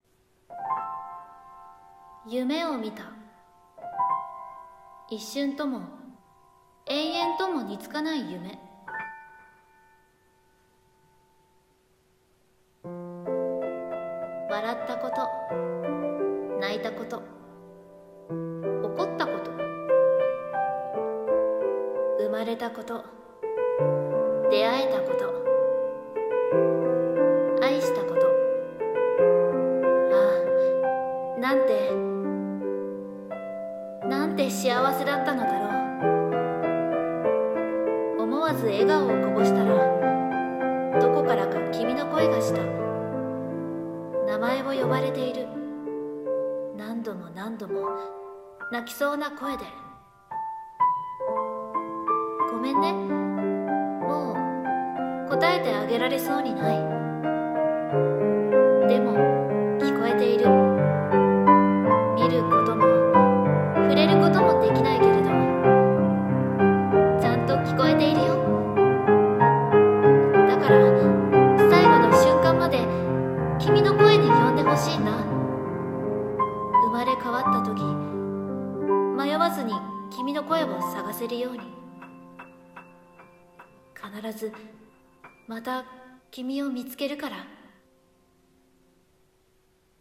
朗読台本「いのちの名前